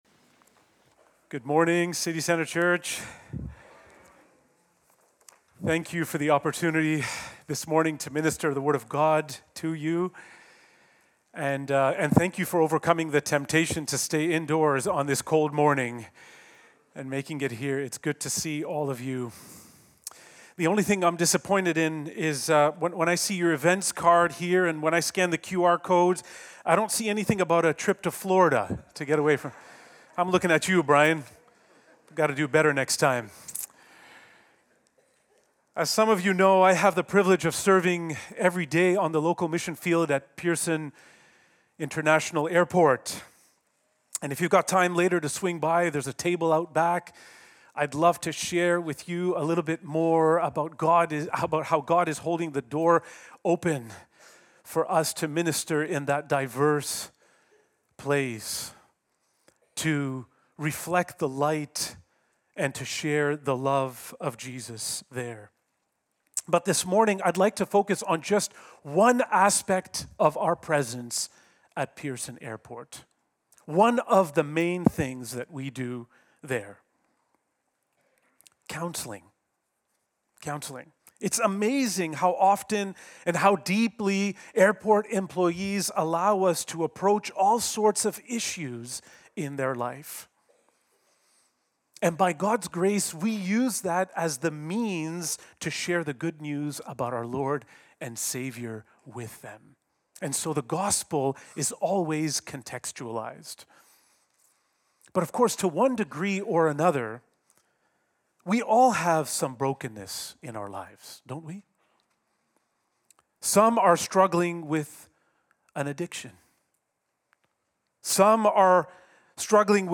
The sermon called us to understand forgiveness not as a feeling, but as a faithful, obedient response flowing from a transformed heart.